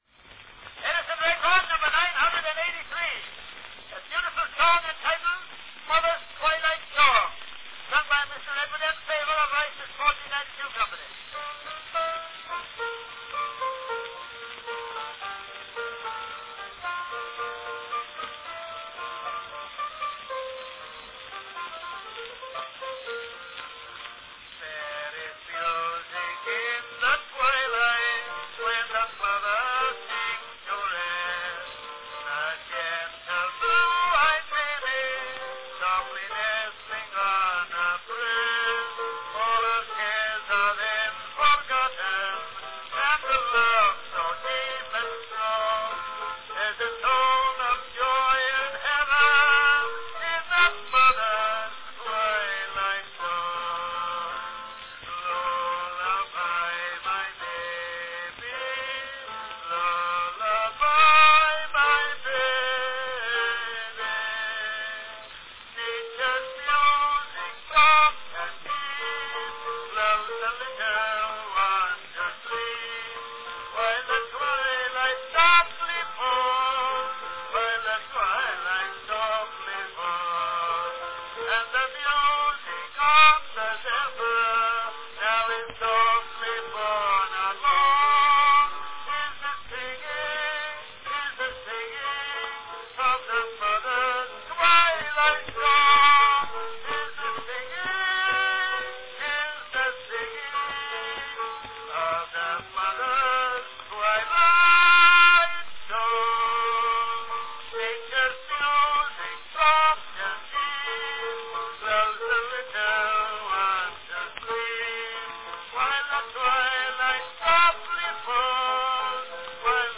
An early wax cylinder recording remembering mother –
Category Song
Also typical of the state of the art, this particular cylinder was a copy made by means of a hollow tube carrying the sound from the master phonograph to the duplicating phonograph.   Tube-copied cylinders possess a classic and distinctive "hollow" sound and are prone to acoustic distortion during louder passages.